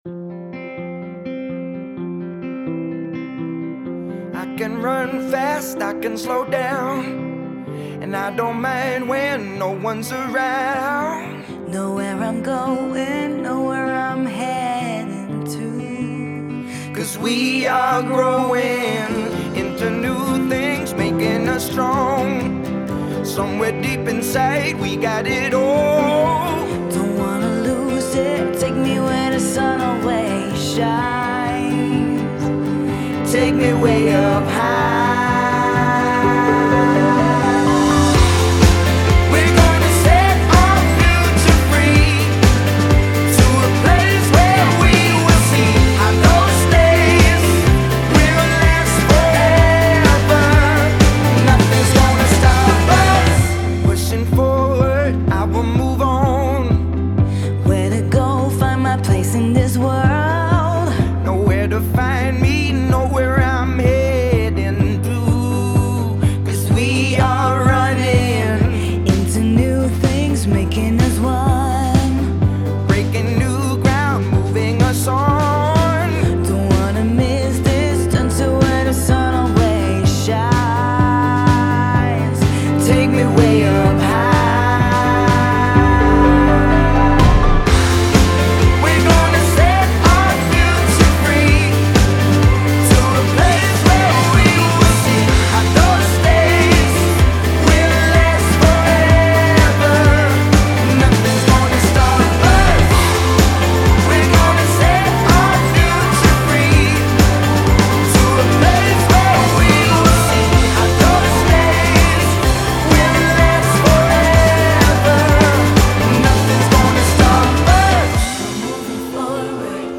a contemporary anthem and a distinctive sound logo